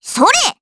Estelle-Vox_Attack3_jp.wav